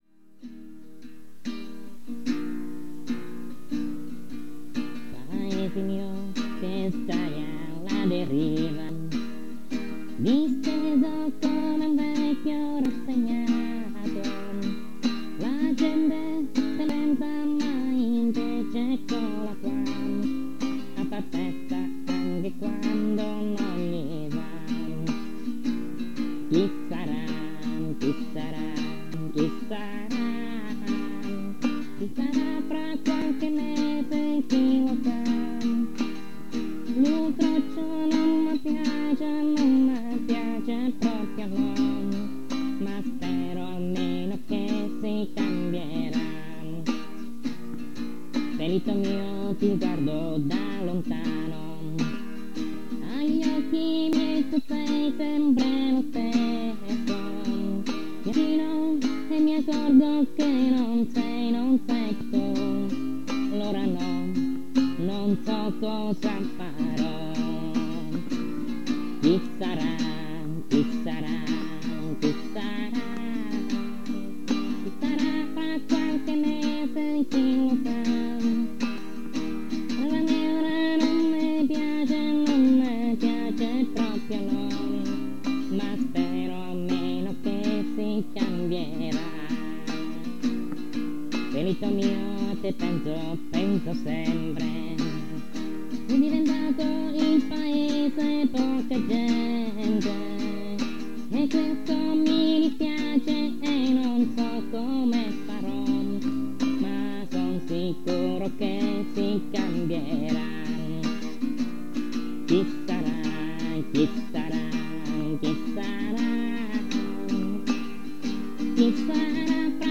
Vi proponiamo una canzone inviataci da un lettore del sito!